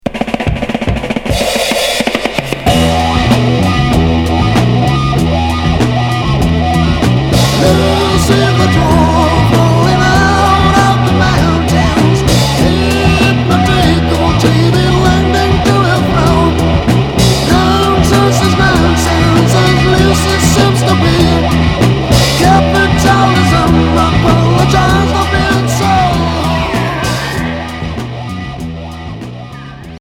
Heavy psyché Premier 45t retour à l'accueil